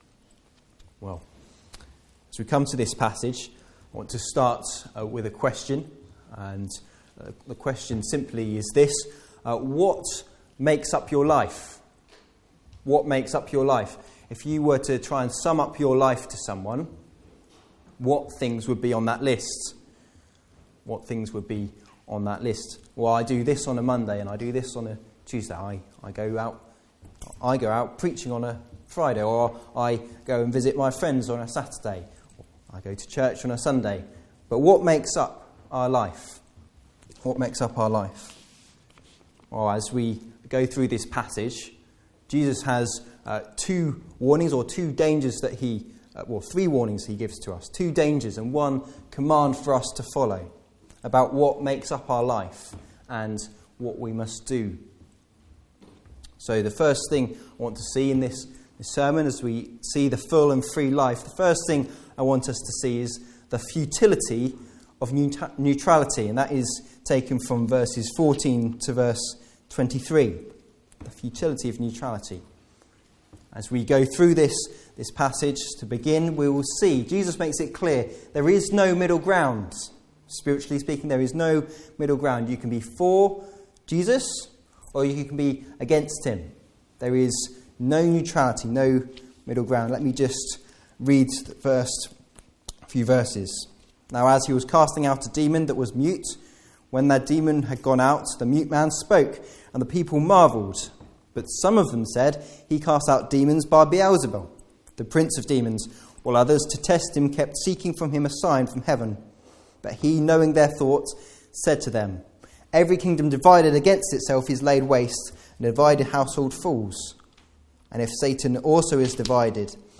Passage: Isaiah 2: 1-11 Service Type: Afternoon Service